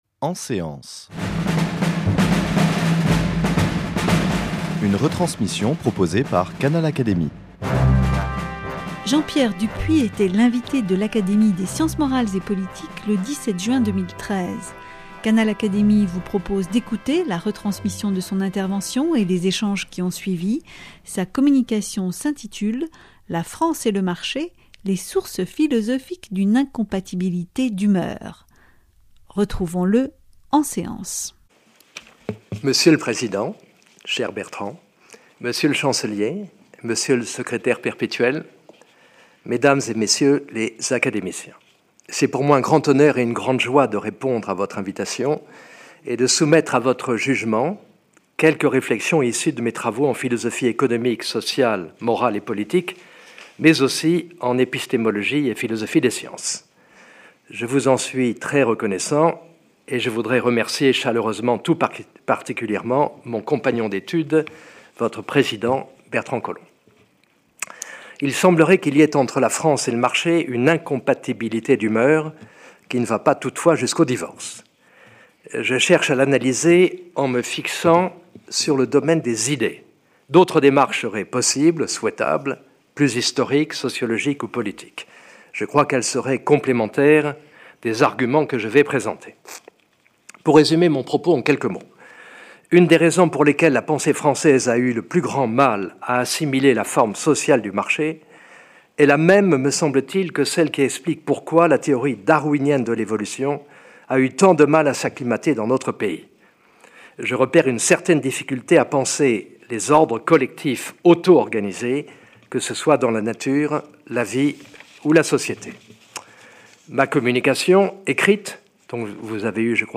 L’histoire et la pensée politique libérale vues par Jean-Pierre Dupuy, une intervention suivie des échanges entre l’orateur et les académiciens.